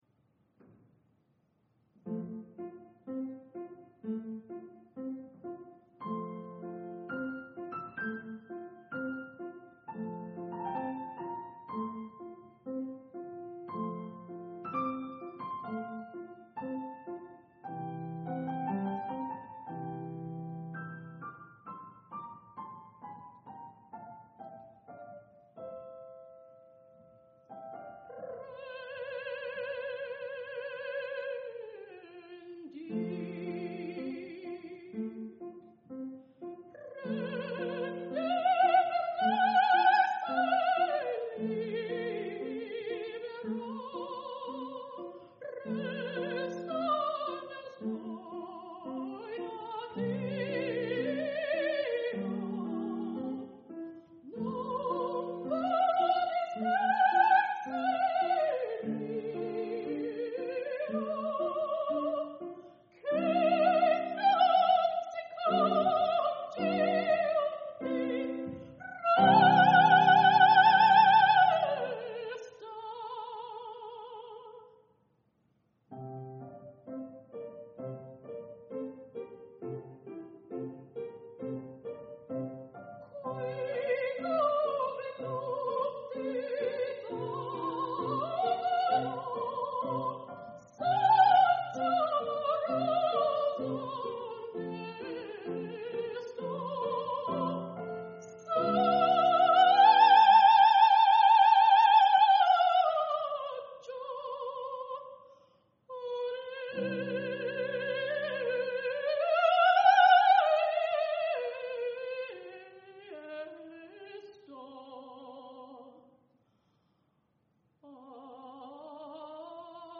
Music: Solo Recital
Instrument or voice part in the sample: soprano